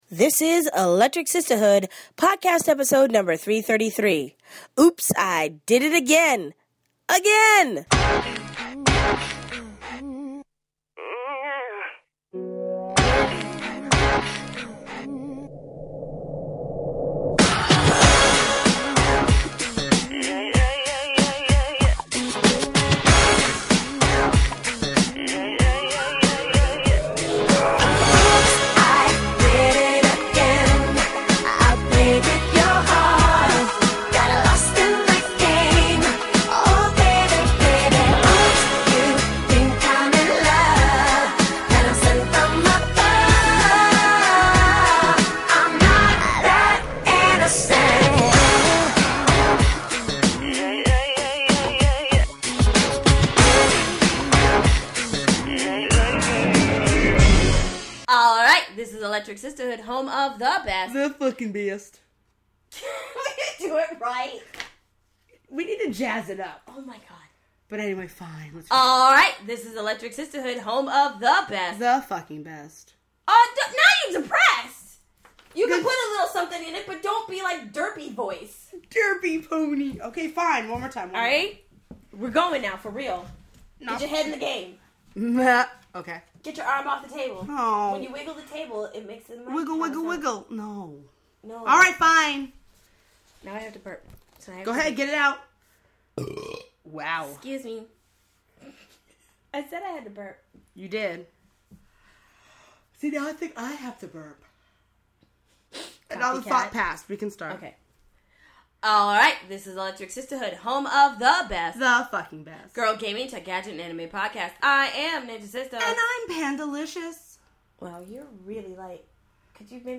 So much so that they put together a clip show for you this week.